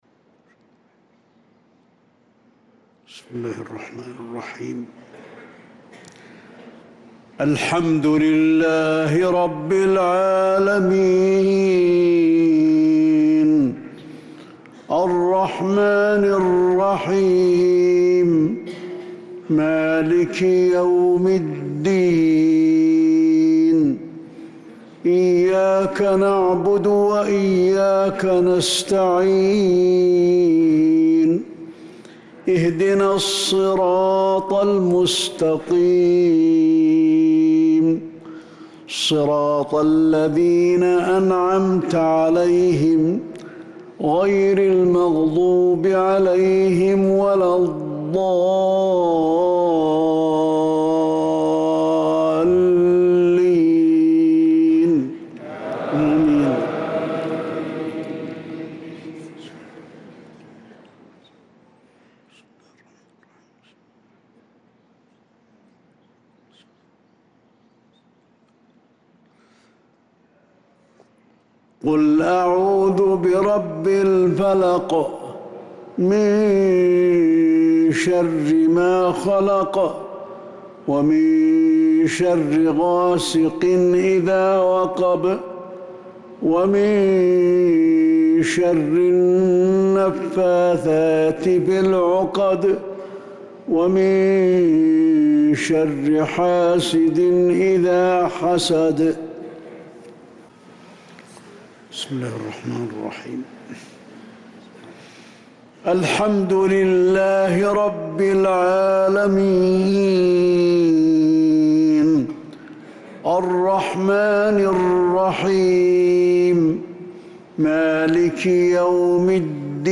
صلاة المغرب للقارئ علي الحذيفي 27 شوال 1445 هـ
تِلَاوَات الْحَرَمَيْن .